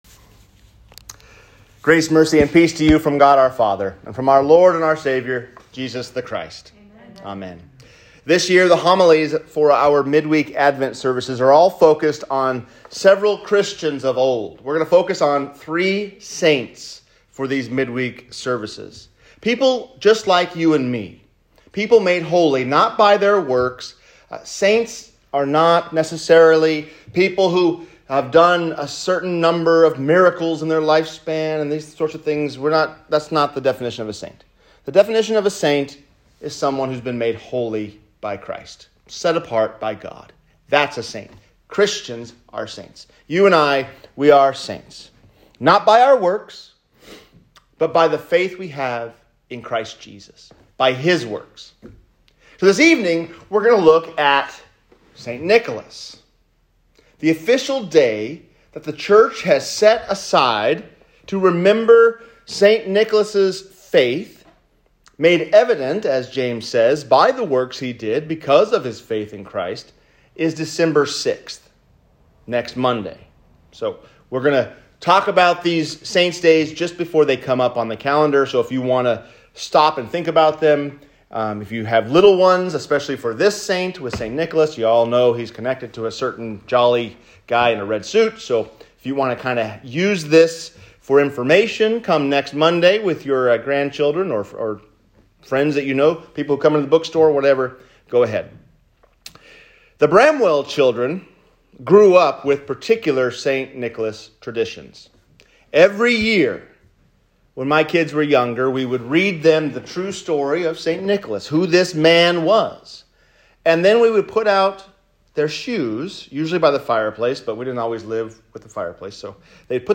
St. Nicholas & Steadfast Faith | Sermon